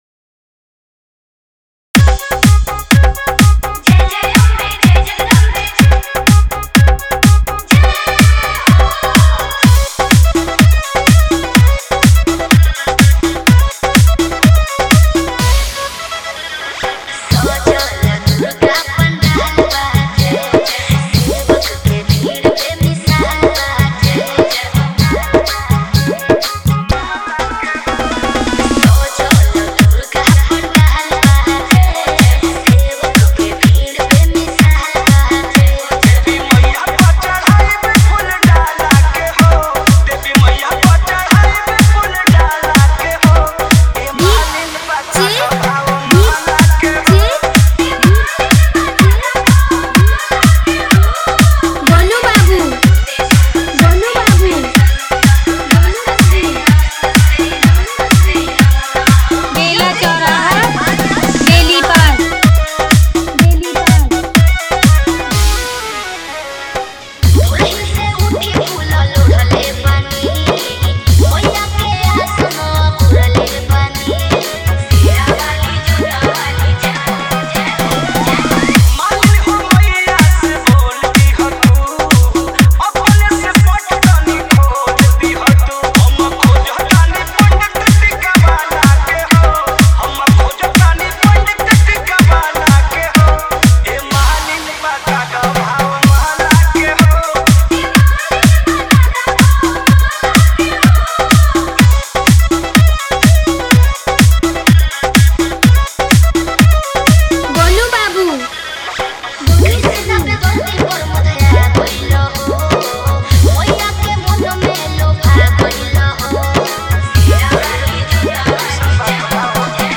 Bhakti Dj Songs